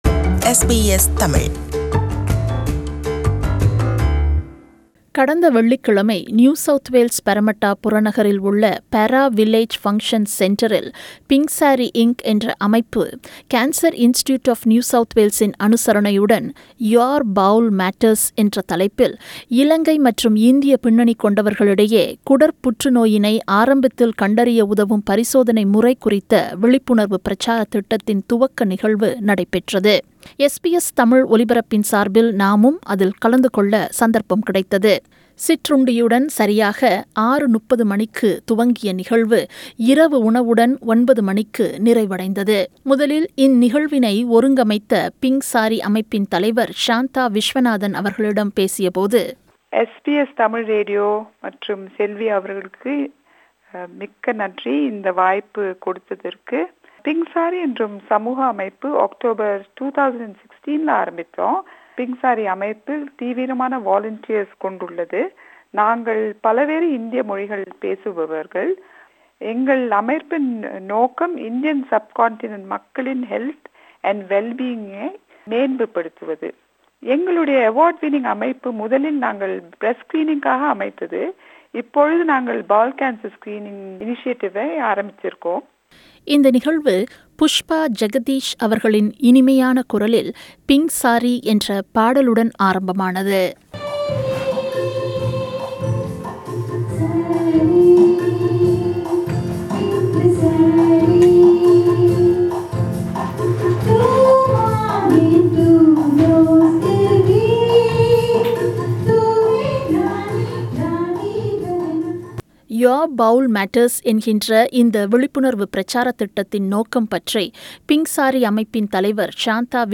Pink Sari Inc in association with Cancer Institute NSW have launched Bowel Cancer Screening awareness campaign "Your Bower Matters" last Friday 29th March 2019 at ParraVilla Function Centre Parramatta. This feature is a report about that campaign function.